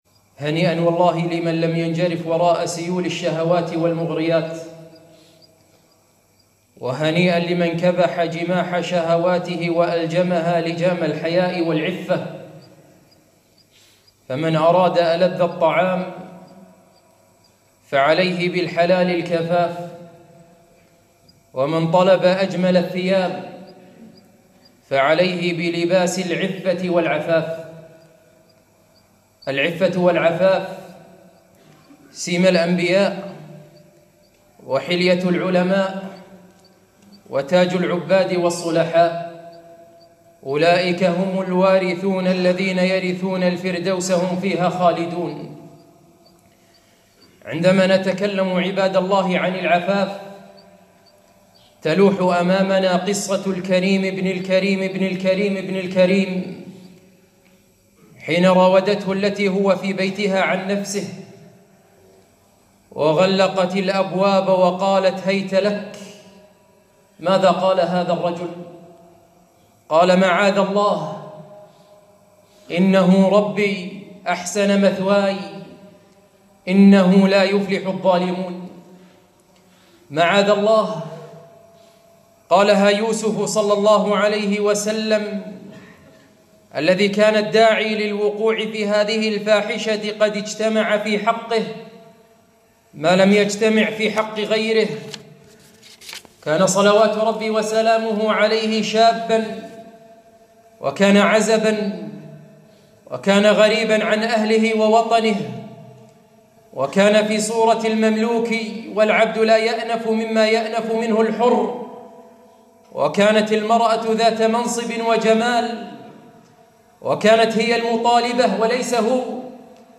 خطبة - أجمل الثياب